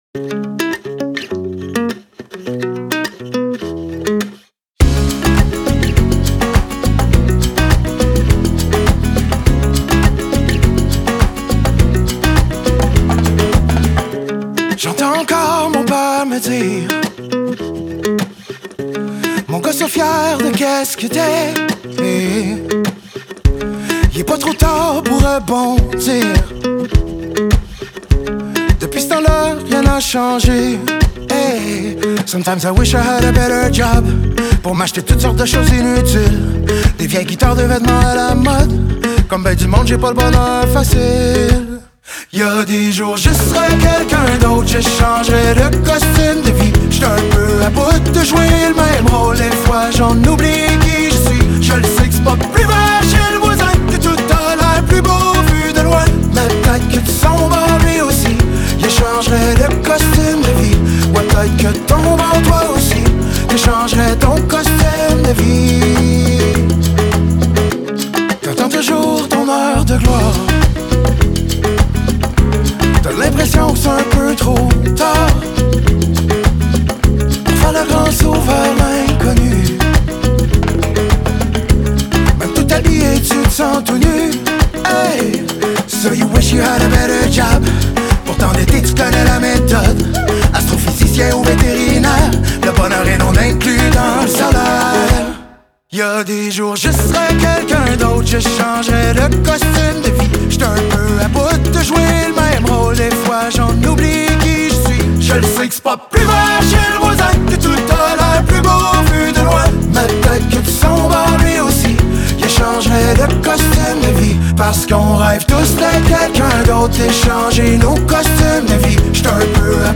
feelgood song